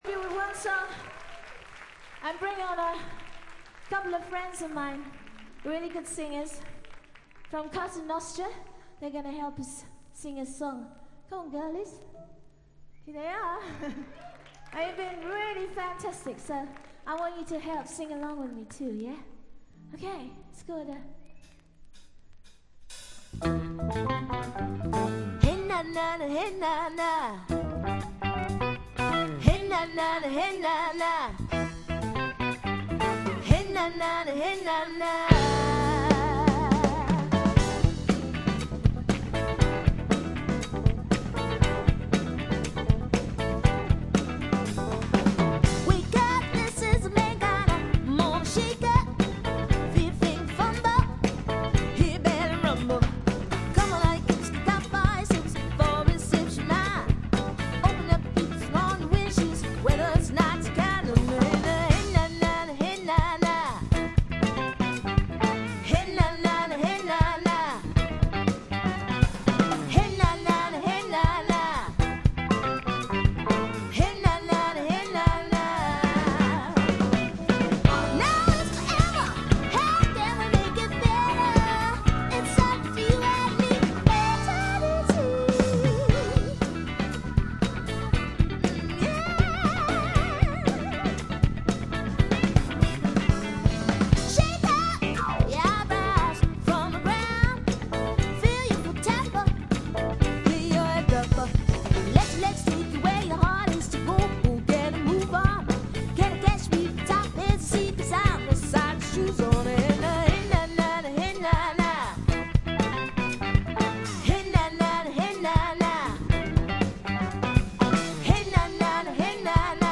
95年10月20、21日に渋谷On Air Eastで行われたライブをほぼステージに忠実に再現した2枚組です。
試聴曲は現品からの取り込み音源です。
Vocals, Acoustic Guitar, Percussion